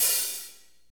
HAT F R H25L.wav